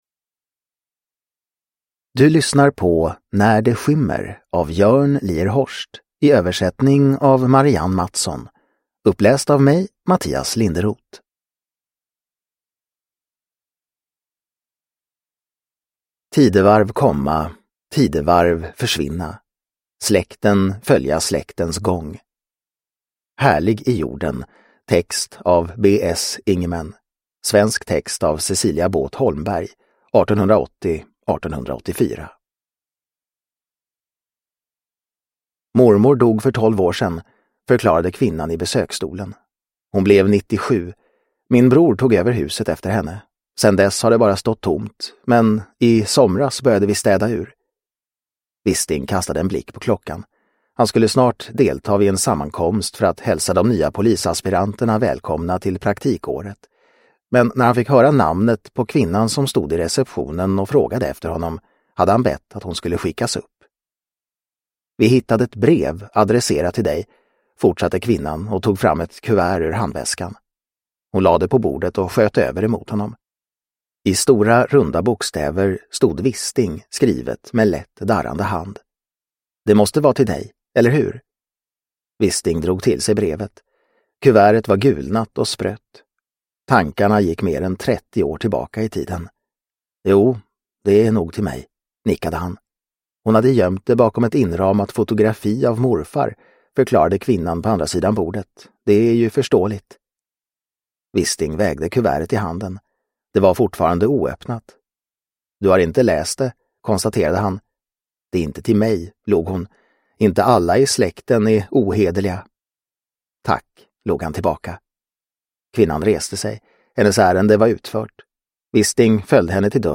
När det skymmer – Ljudbok – Laddas ner